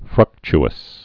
(frŭkch-əs, frk-)